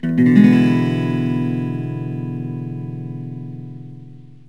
E7.mp3